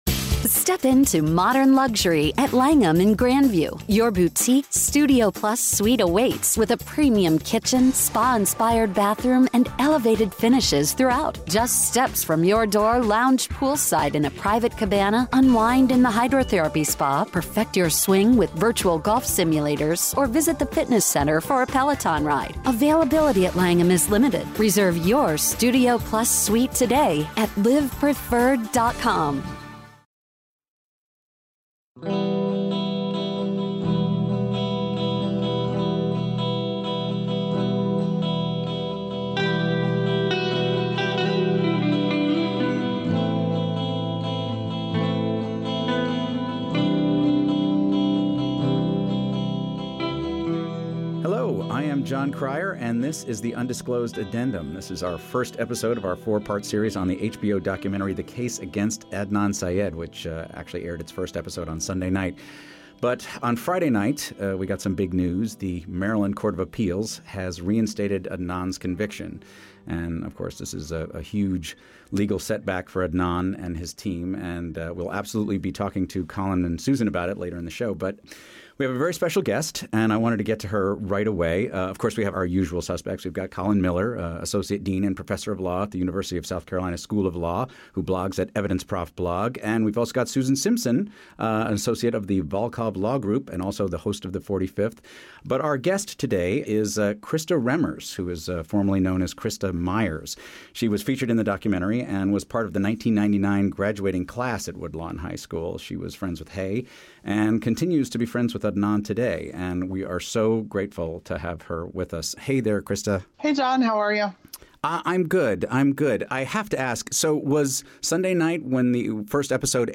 March 14, 2019 / Jon Cryer is back to host our first addendum covering HBO's docuseries.